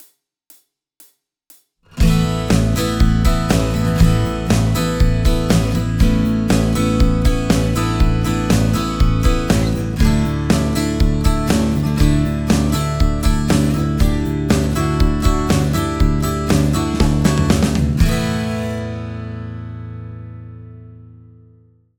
ギター：Gibson J45
マイクプリ：SSL XLogic（EQやコンプは不使用）
録音後には一切処理を行わず、そのまま書き出しています。
4/4拍子 ストレートな開放コードのレコーディング
LCT 440 PURE バンドミックスサンプル（ジョイント部分を狙ったもの）